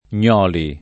[ + n’ 0 li ]